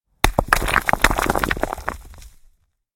На этой странице собраны звуки камнепада — от легкого шелеста скатывающихся камешков до грохота крупных обвалов.
Звуки камнепада: упавший камень и его перекатывание (шум осыпающихся камней у края скалы)